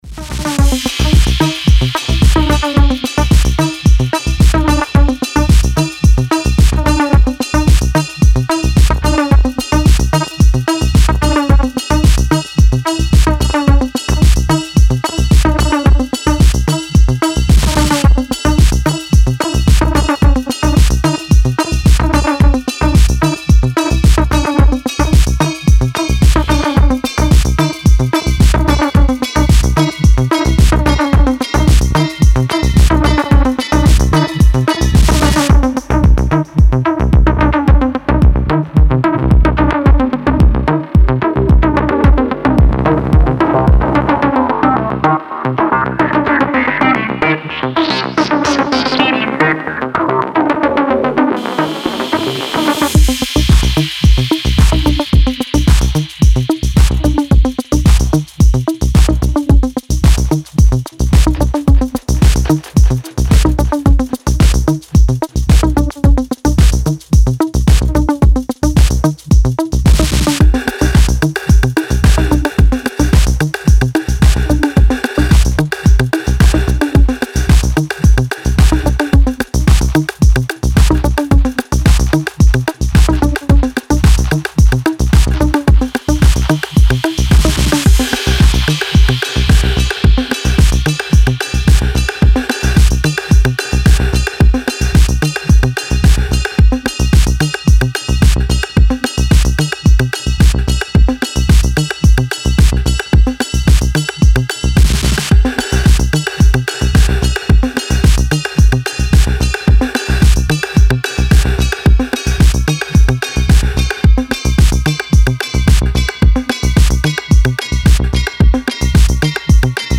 Style: House / Tech House / Acidish